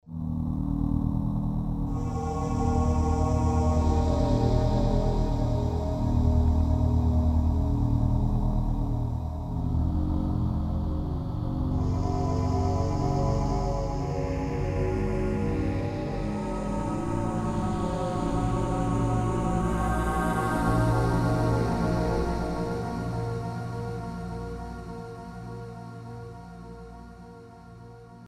Digital Synthesizer Module (1988)
demo ambient: 1 -
- Lo-fi industrial sound
choir-lofi.mp3